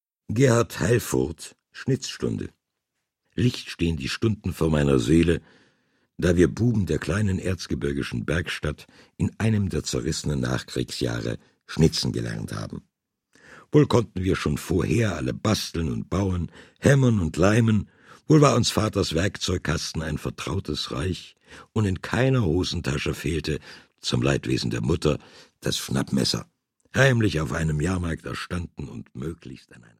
Und weil die meisten mündlich überliefert wurden, sind es Mundart-Geschichten auf „Arzgebirgisch“.
Deutsch - Mundart